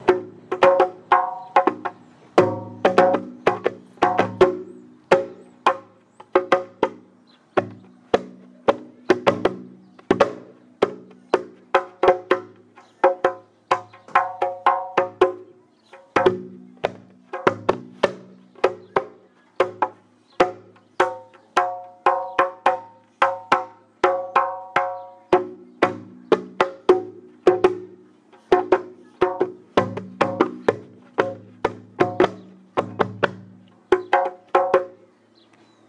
African drum